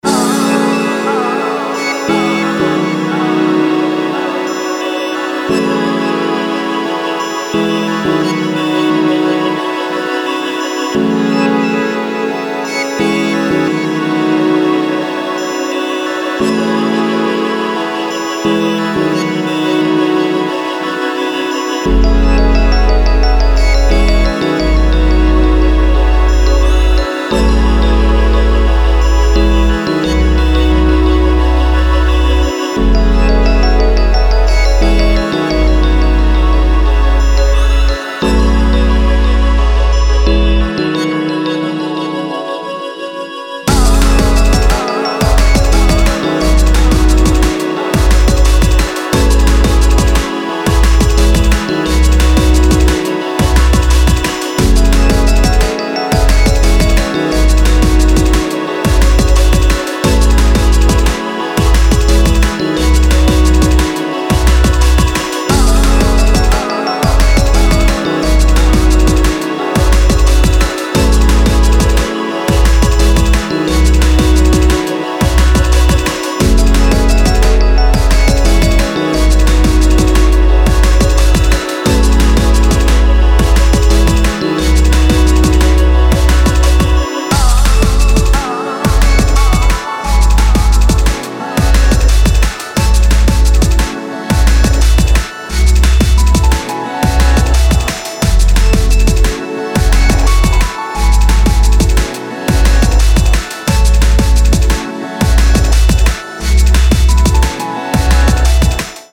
Genres Drum & Bass